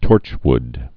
(tôrchwd)